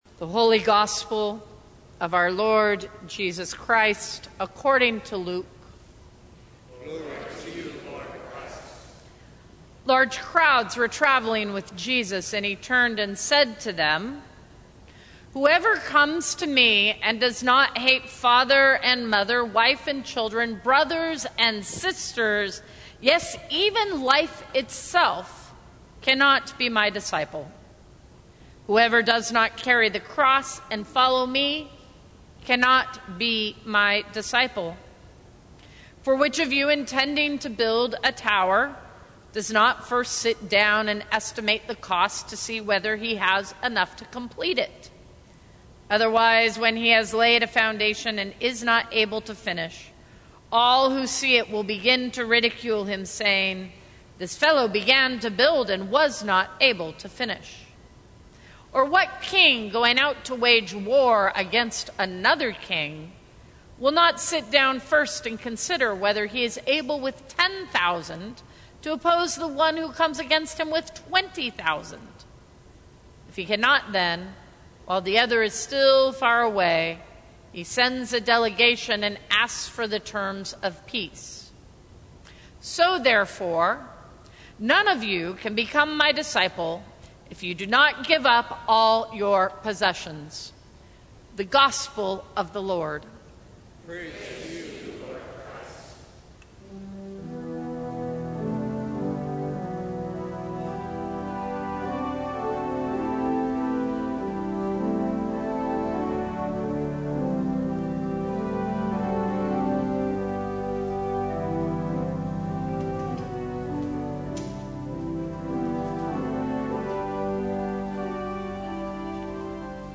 Sermons from St. Cross Episcopal Church Choosing God Sep 16 2019 | 00:16:30 1x 00:00 / 00:16:30 Subscribe Share Apple Podcasts Spotify Overcast RSS Feed Share Link Embed